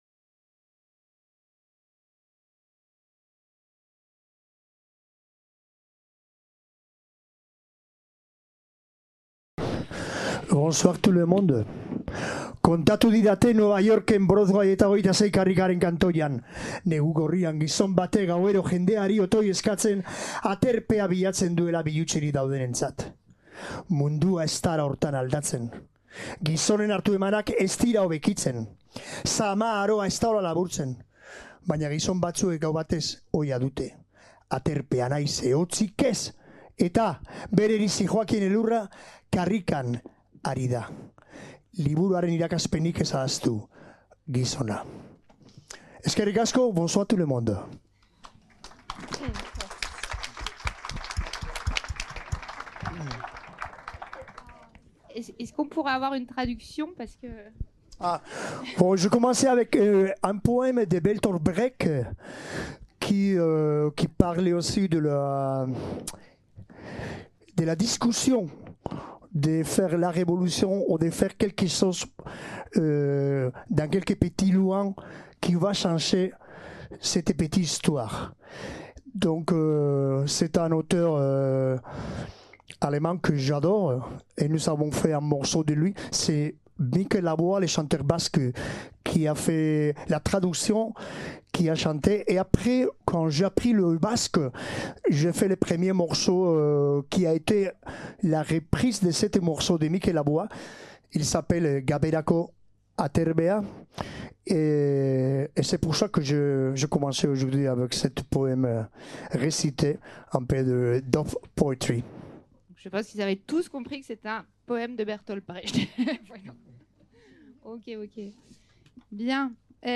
Entretien avec le chanteur et cinéaste, Fermin Muguruza lors du festival international Bruits de Langues 2023